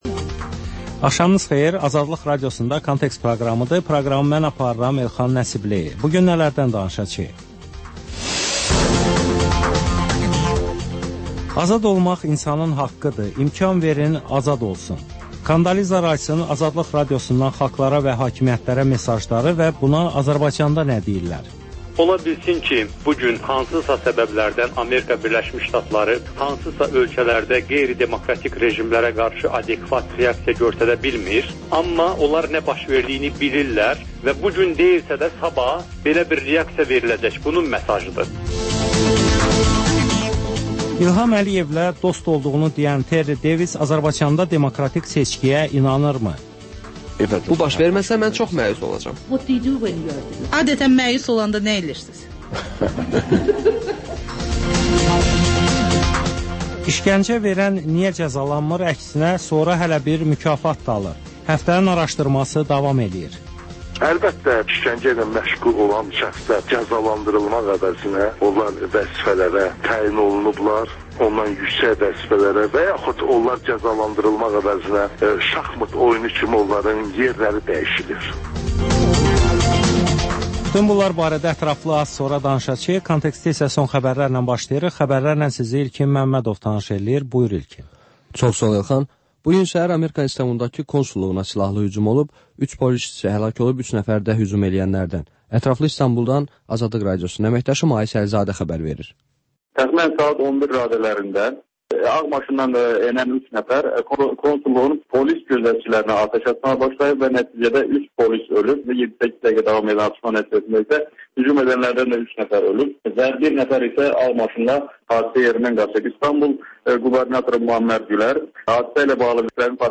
Xəbərlər, müsahibələr, hadisələrin müzakirəsi, təhlillər, daha sonra 14-24: Gənclər üçün xüsusi veriliş